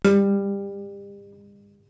Source: Pizz minus attack (11:18-11:35)
Processing: granulated, amp. correlated @ max=50 + 3 lower harmonics
Pizz_Soft_Attack.aiff